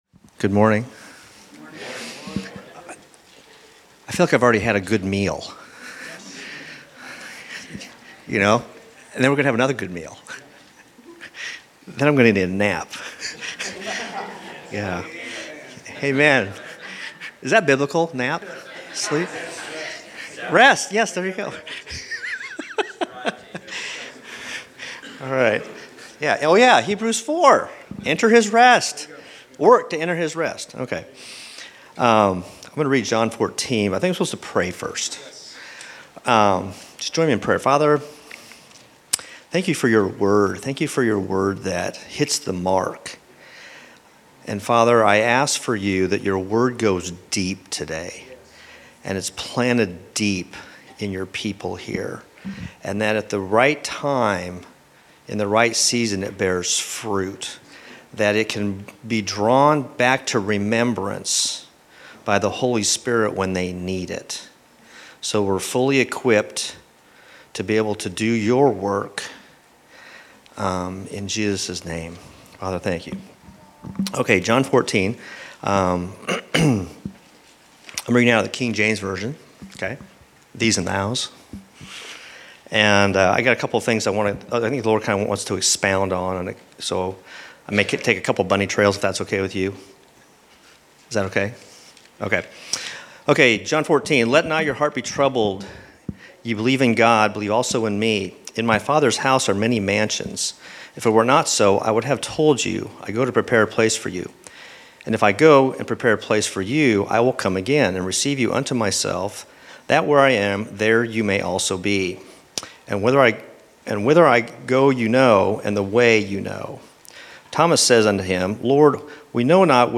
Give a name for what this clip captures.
Category: Scripture Reading